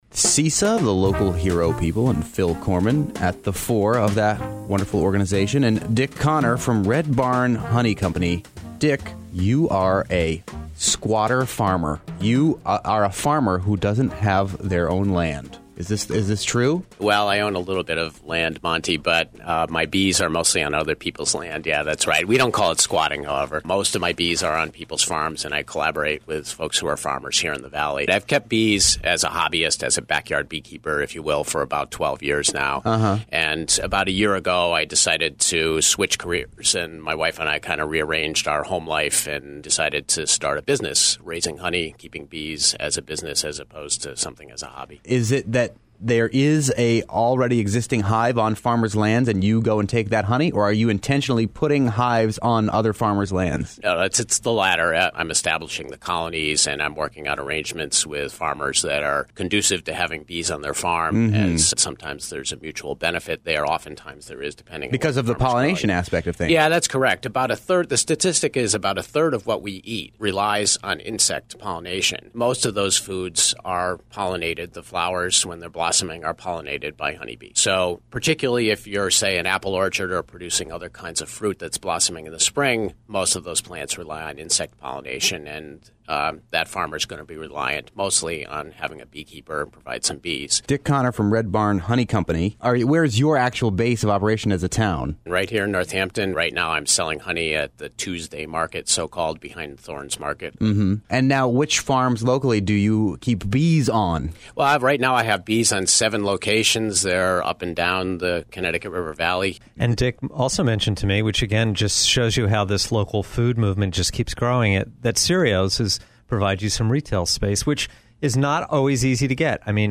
Interview: Red Barn Honey